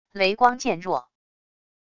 雷光渐弱wav音频